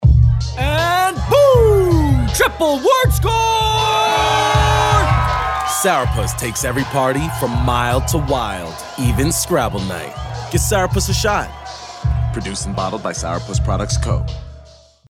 Commercial (Sour Puss) - EN